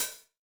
INT Open Hat.wav